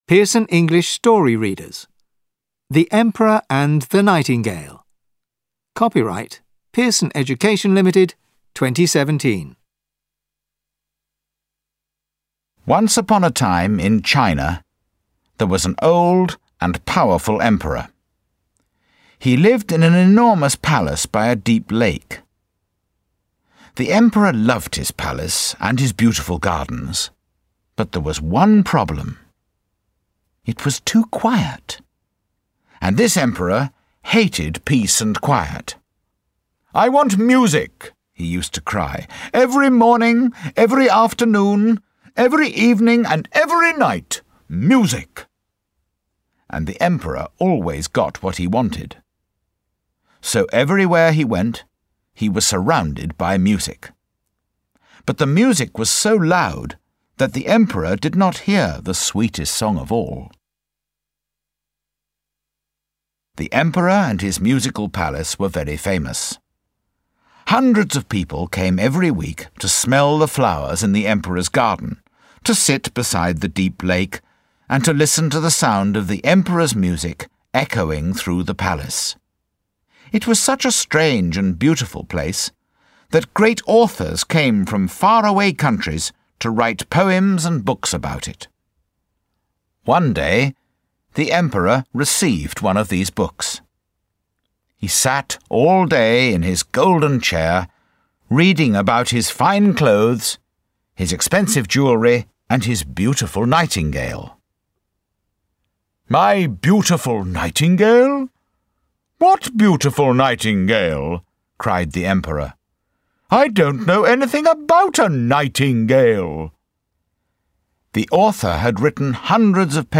Emperor_Nightingale_L4_BrE.mp3